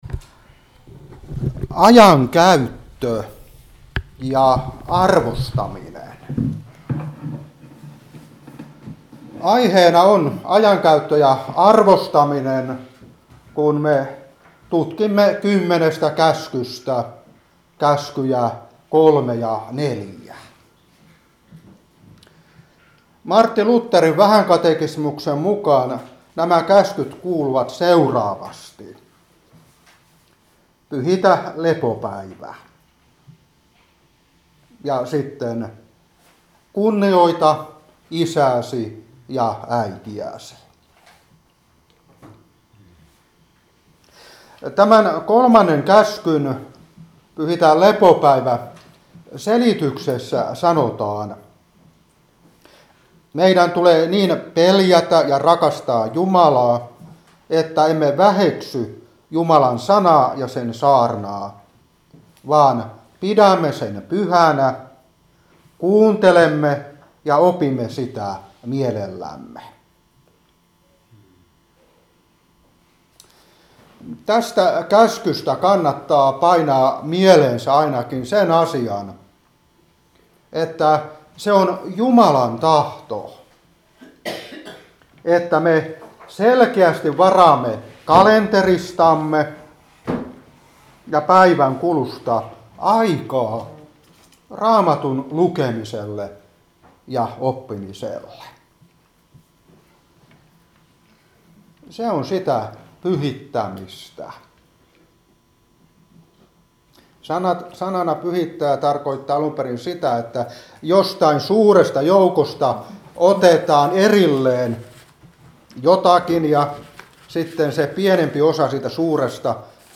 Opetuspuhe 2023-6. Käskyjen 3 ja 4 selitystä. 1.Moos.2:1-3.